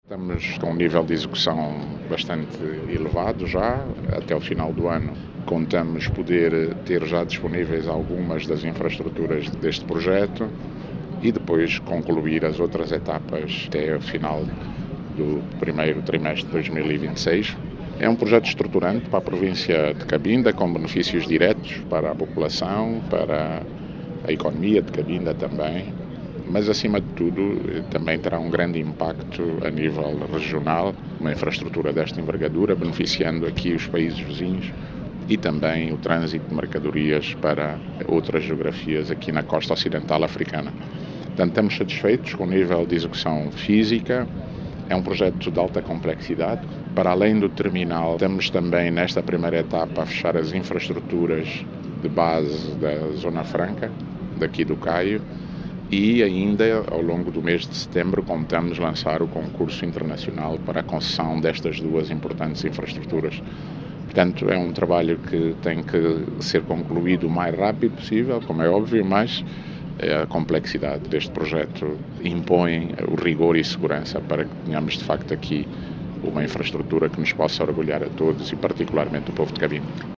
O ministro dos Transportes, Ricardo D’Abreu, avançou que, numa primeira fase, a infra-estrutura vai dar uma nova dinâmica à economia de Cabinda e às economias da costa ocidental africana.